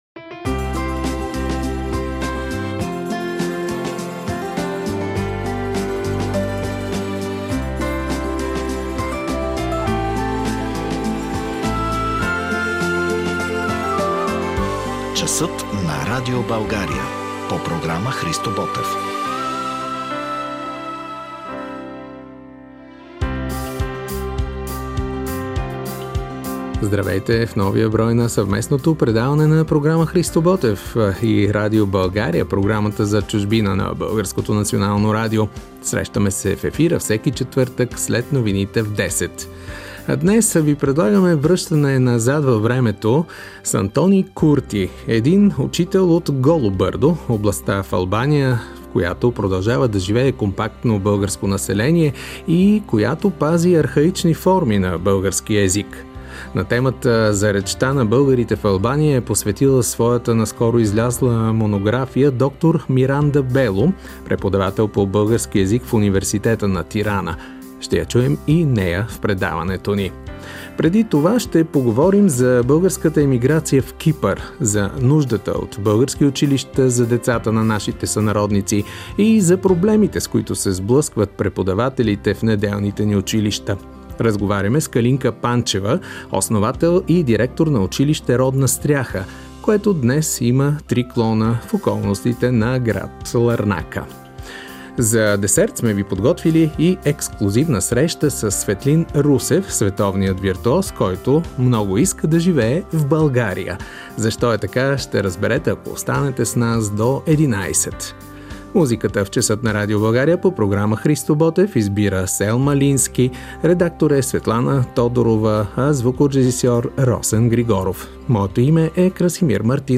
Интервю с именития ни цигулар.